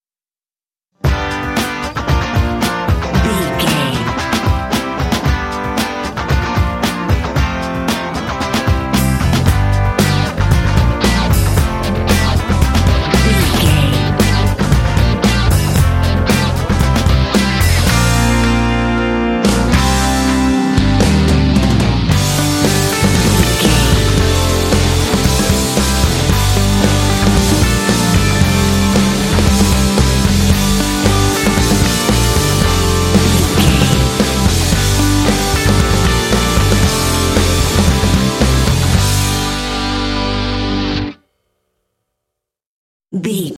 Driving indie track, reminiscent of skateboard-rock.
Aeolian/Minor
bouncy
happy
groovy
bright
electric guitar
bass guitar
drums
alternative rock
indie